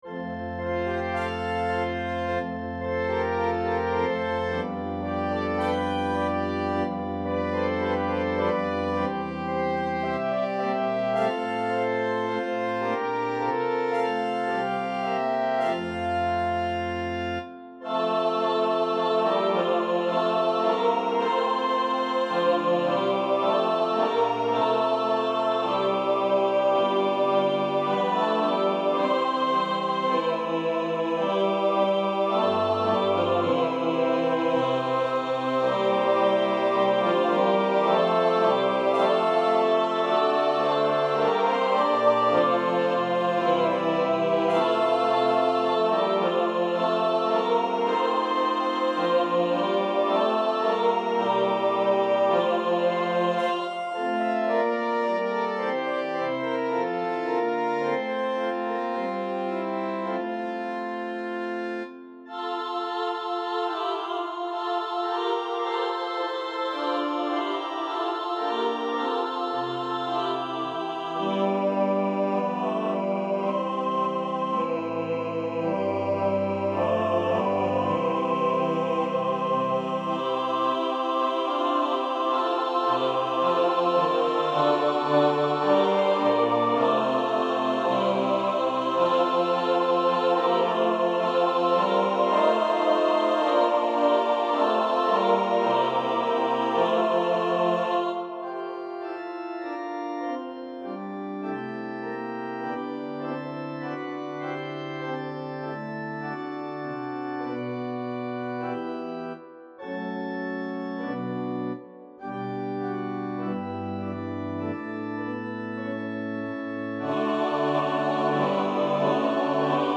• Music Type: Choral
• Voicing: SATB
• Accompaniment: Organ, Trumpet
Festive hymn-anthem